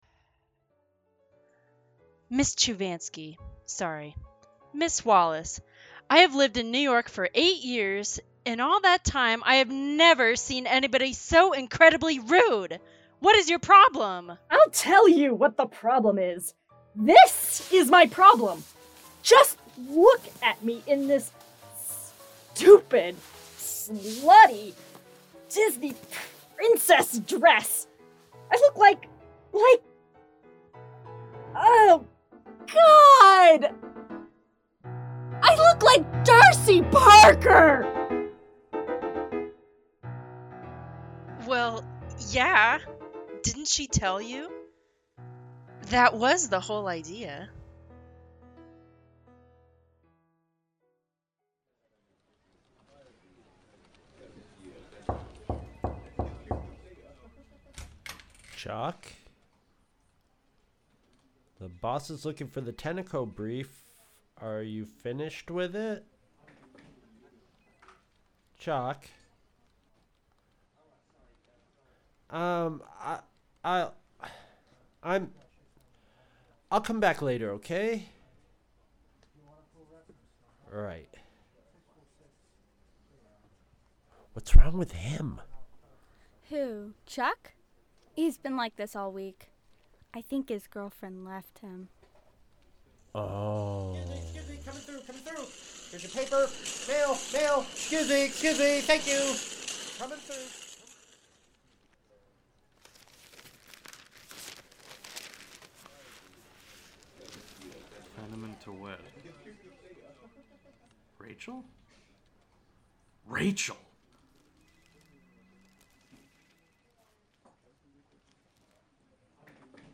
strangers-in-paradise-the-audio-drama-book-5-episode-13.mp3